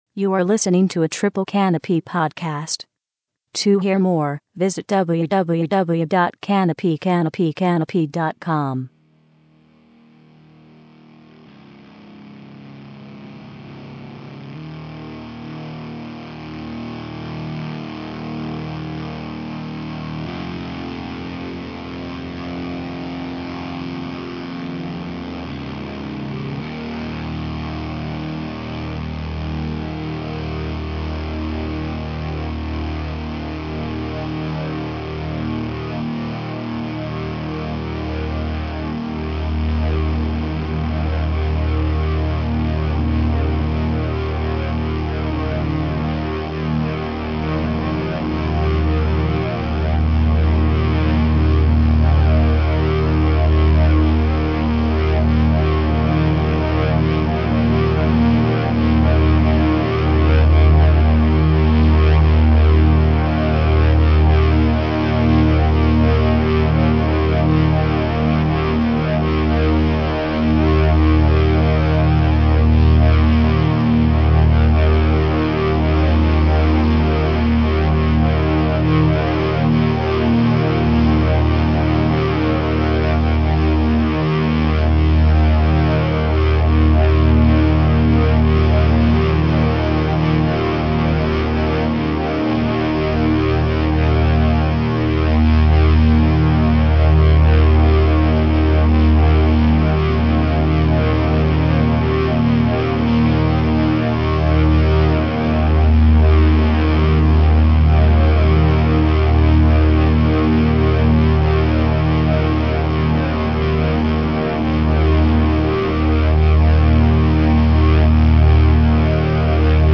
The program consisted of seven hours of sound work.